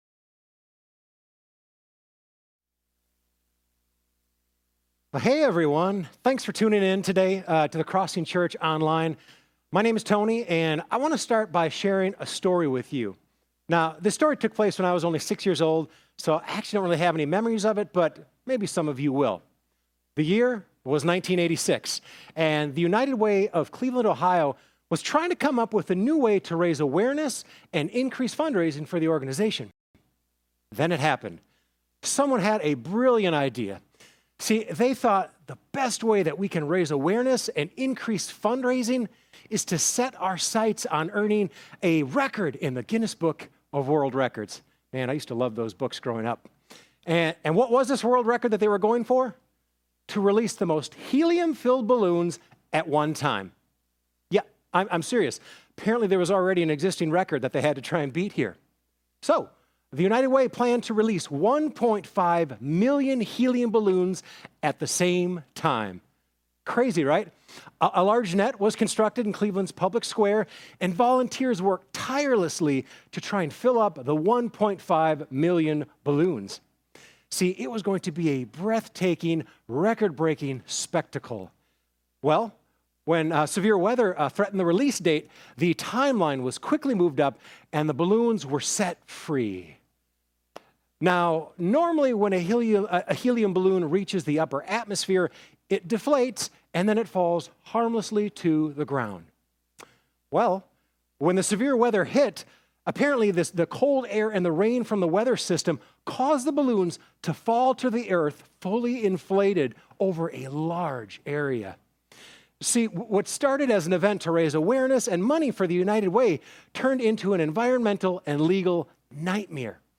The sermon said it’s not wrong to desire reward, but we often seek it from the wrong source.